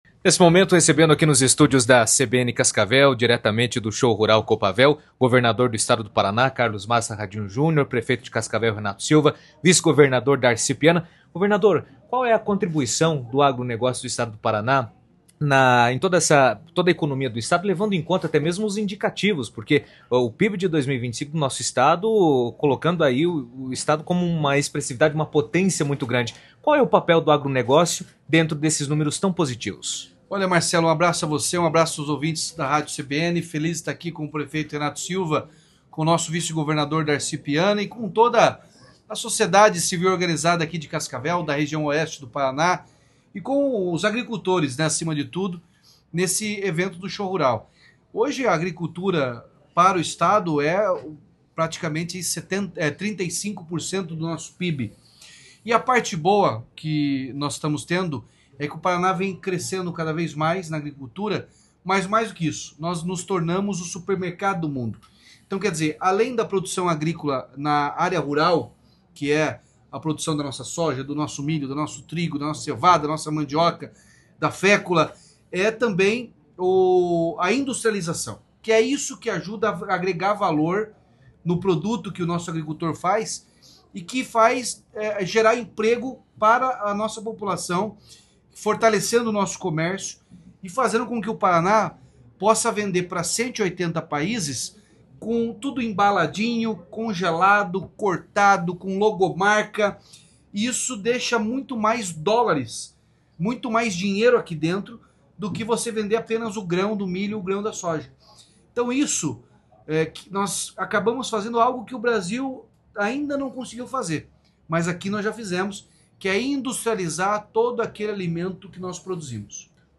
O governador do Paraná, Ratinho Júnior, marcou presença no primeiro dia do Show Rural Coopavel, em Cascavel, onde participou de anúncios e apresentou investimentos voltados ao setor produtivo. Durante passagem pelo estúdio da CBN, o chefe do Executivo estadual falou sobre a importância do agronegócio para a economia paranaense, destacando o peso do setor na geração de empregos, renda e desenvolvimento regional, e também comentou publicamente sobre sua pré-candidatura à Presidência da República, abordando o cenário político nacional e os desafios econômicos do país.